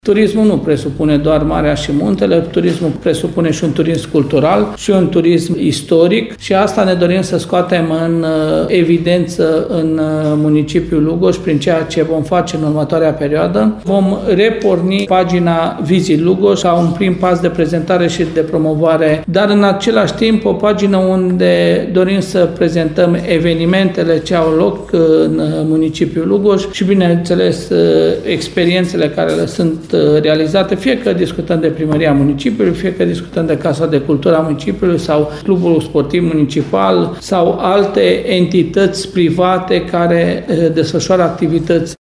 Primarul Călin Dobra spune că relansarea platfomei Visit Lugoj este un prim pas de pentru crearea unei identități turistice.